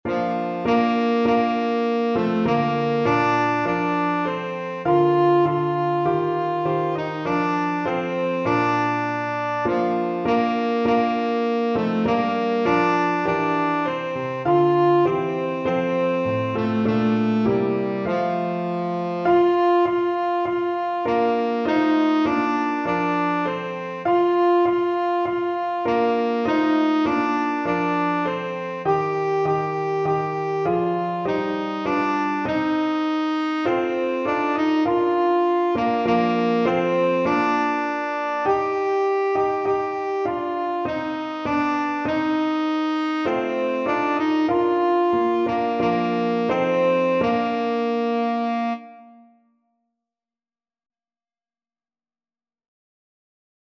Christmas Christmas Tenor Saxophone Sheet Music Hark!
Tenor Saxophone
Bb major (Sounding Pitch) C major (Tenor Saxophone in Bb) (View more Bb major Music for Tenor Saxophone )
4/4 (View more 4/4 Music)
F4-G5
hark_TSAX.mp3